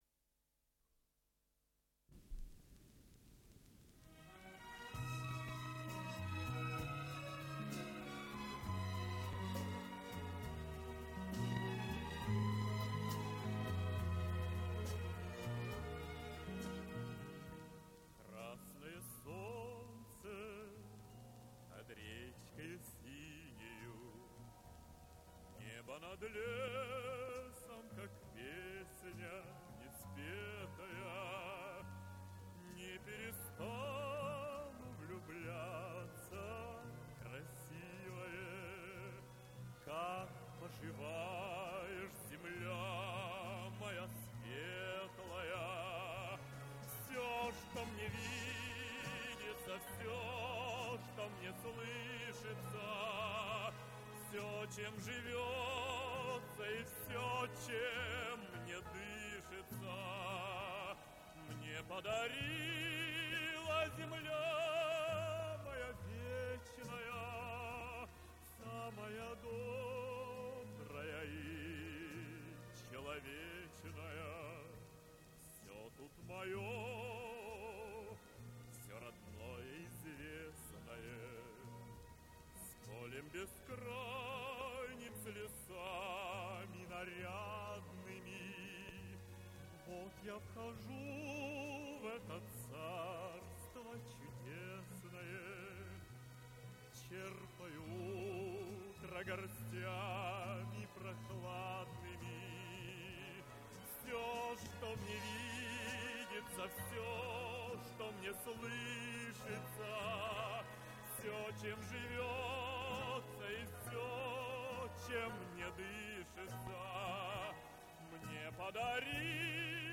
Дубль моно